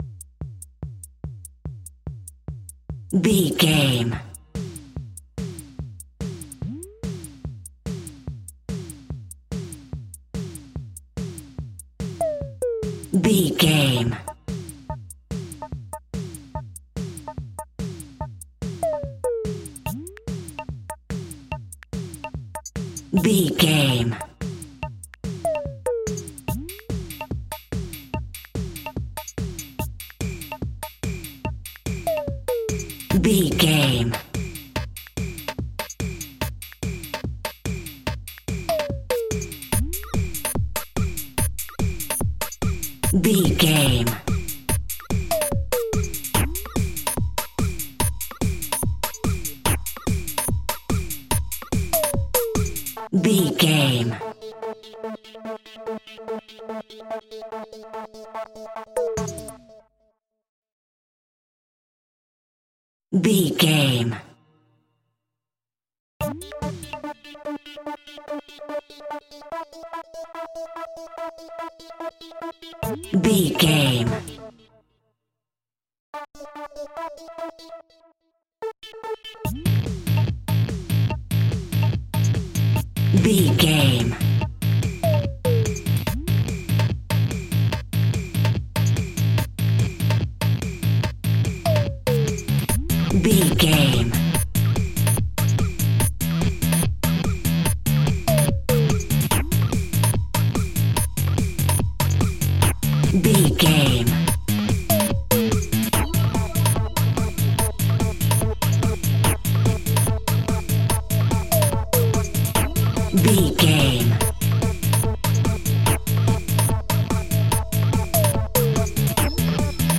Ionian/Major
uplifting
futuristic
hypnotic
dreamy
groovy
drum machine
electric piano
synthesiser
electronic
synthwave